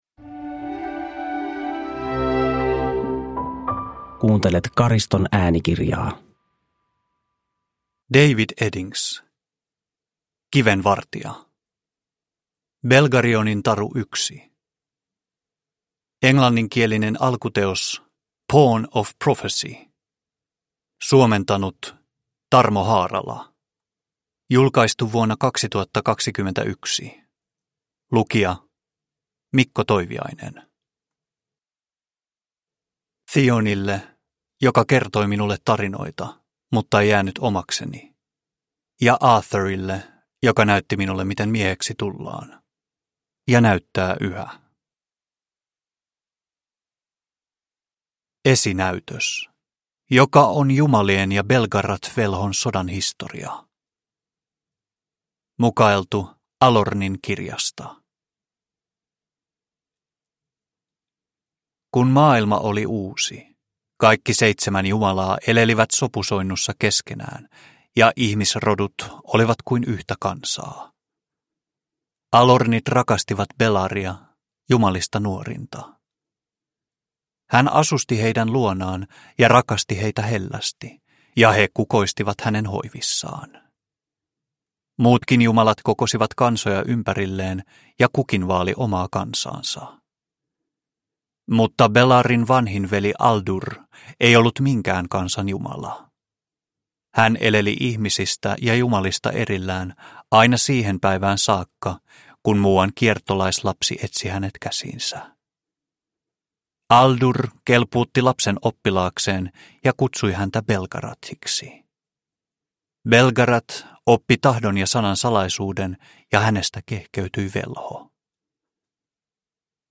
Kiven vartija - Belgarionin taru 1 – Ljudbok – Laddas ner